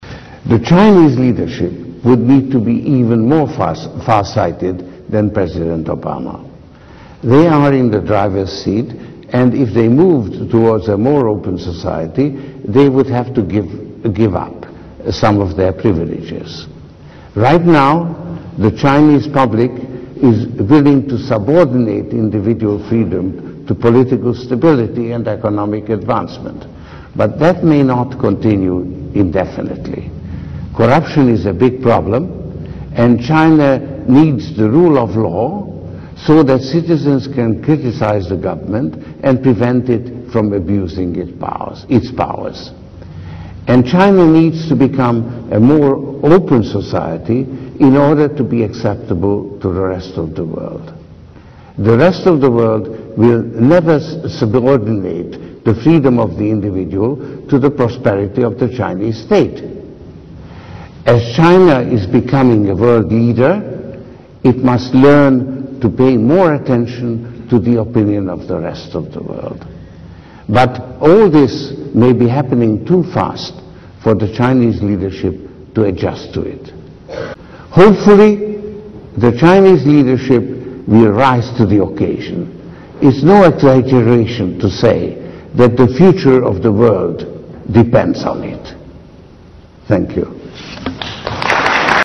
财富精英励志演讲 第148期:未来的路(23) 听力文件下载—在线英语听力室